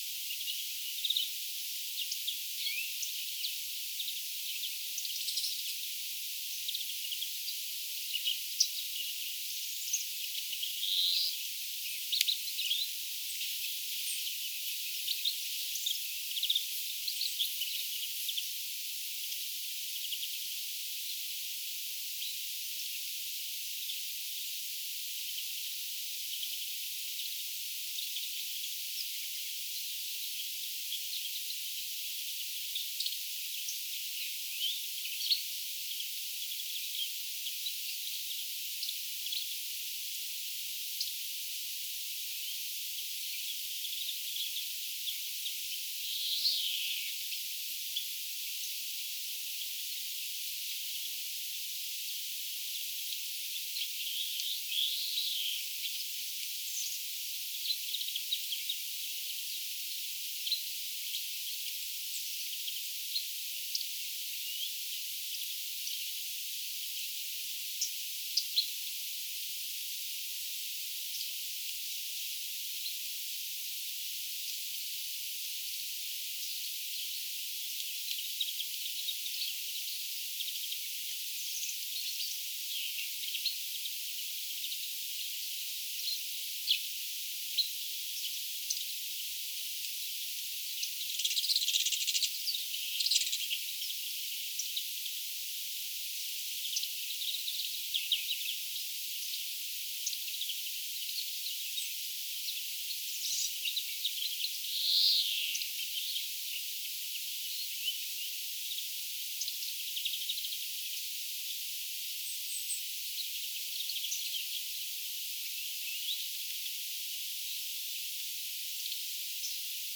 Tänään lauloi siellä jatkuvasti viherpeippo.
lisää viherpeipon laulua
saaren_linturuokinnalla_lauloi_kokoajan_viherpeippolintu.mp3